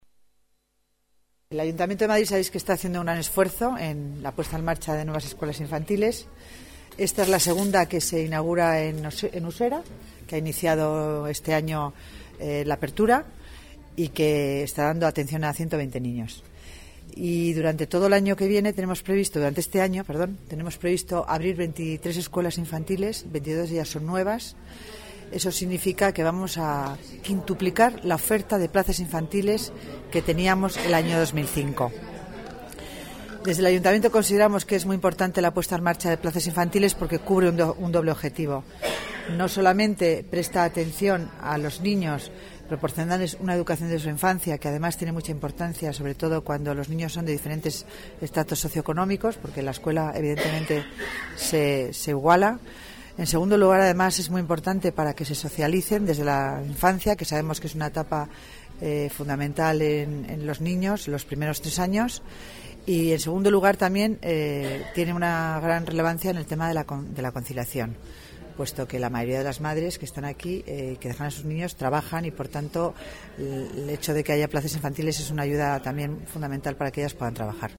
Nueva ventana:Declaraciones de la delegada del Área de Familia y Asuntos Sociales, Concepción Dancausa